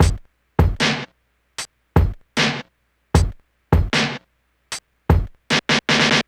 drums06.wav